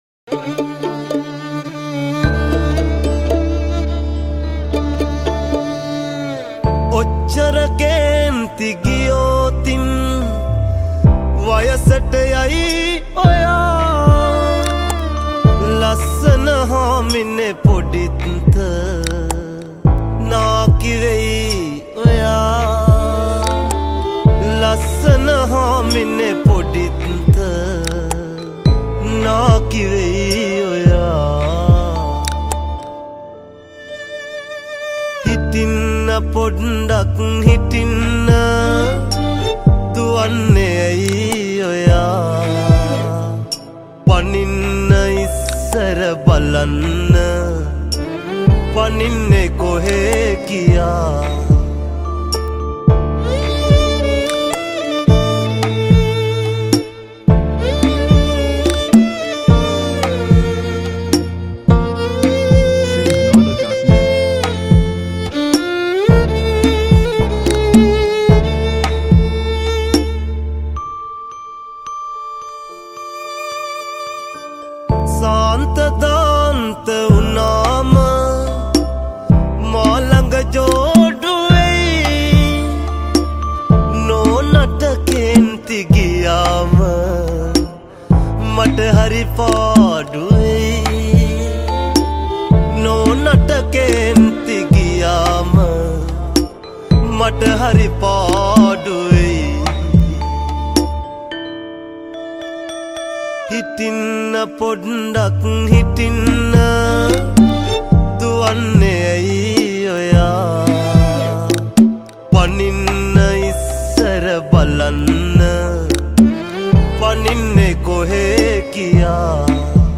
Original Vocals